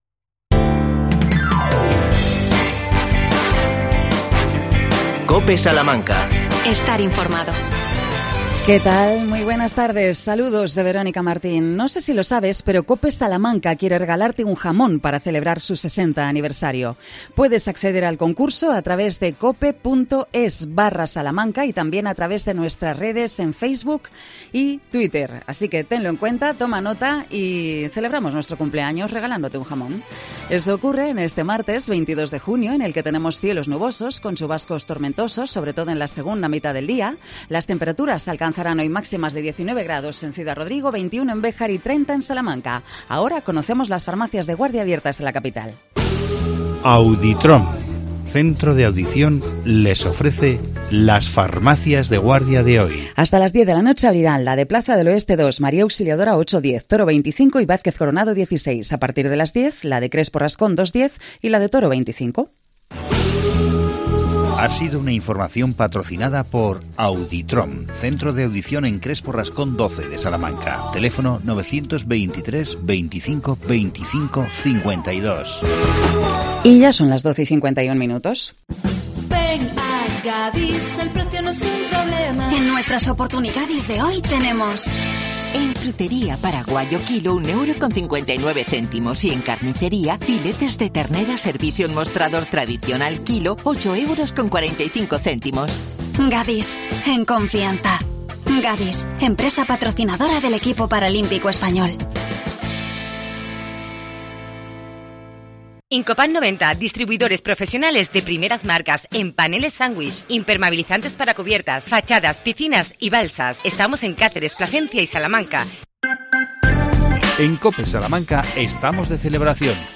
AUDIO: El concejal Fernando Carabias informa sobre la nueva regulación de la carga y descarga para la ciudad de Salamanca.